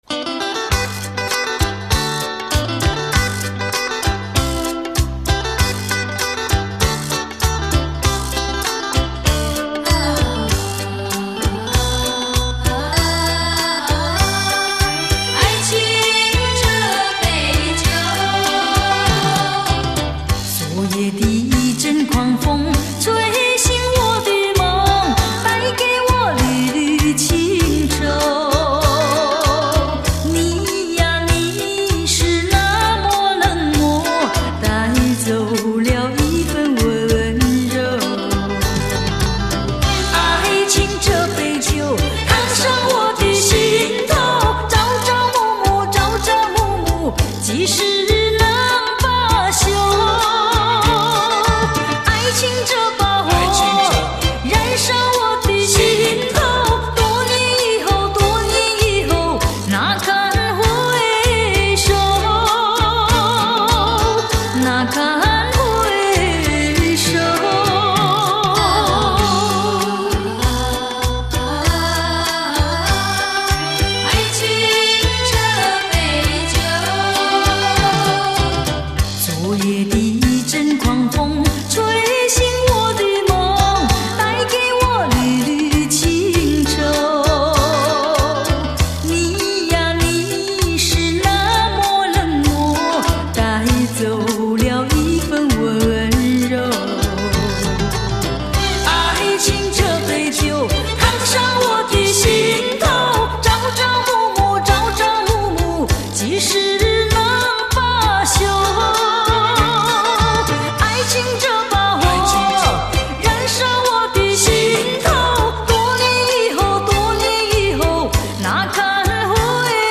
音乐风格: 流行